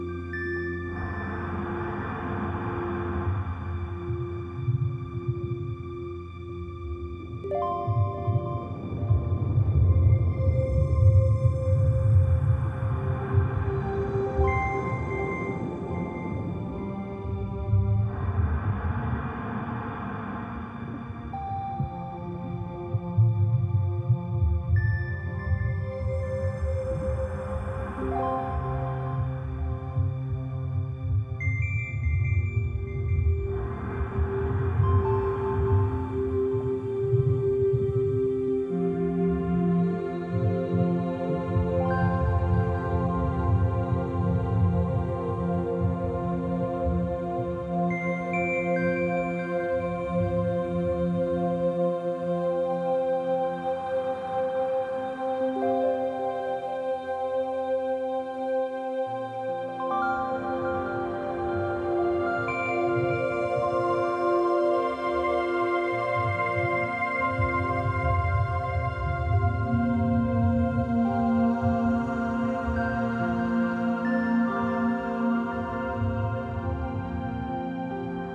2 discs)CD 純音樂(HK$110